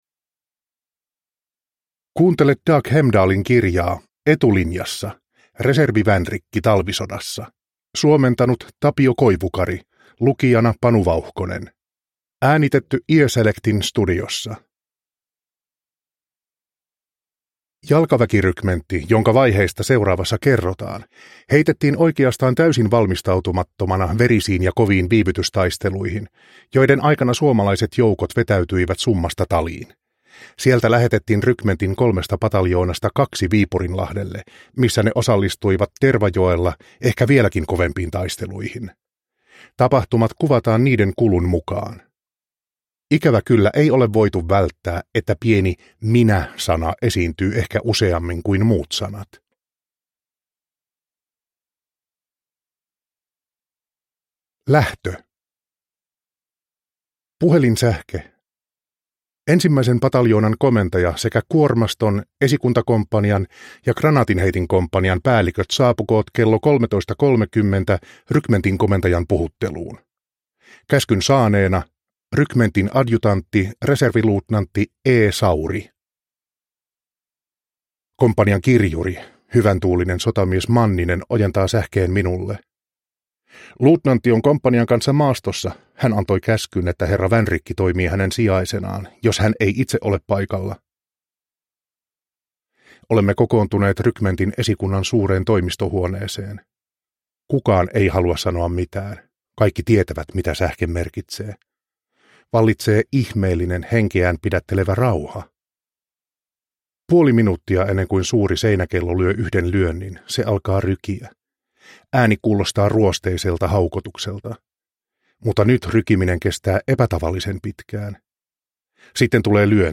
Etulinjassa (ljudbok) av Dag Hemdal